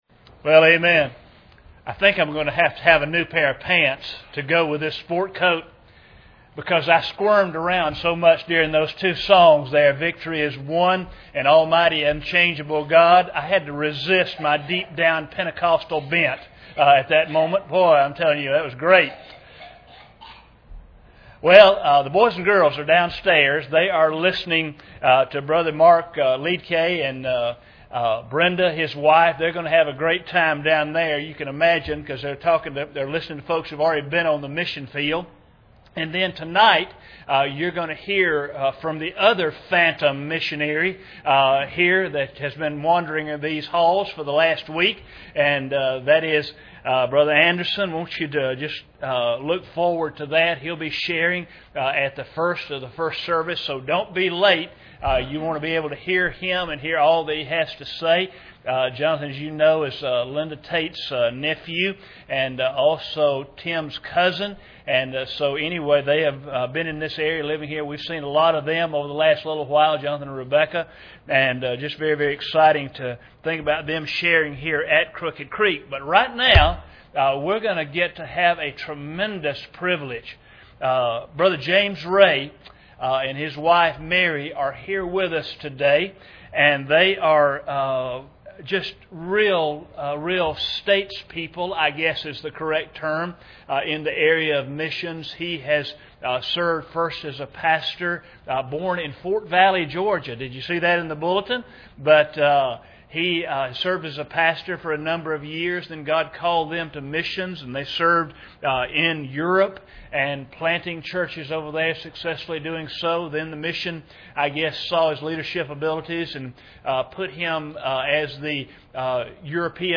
Passage: 2 Corinthians 8:1-24 Service Type: Sunday Morning